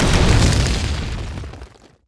effect_stone_hit_0008.wav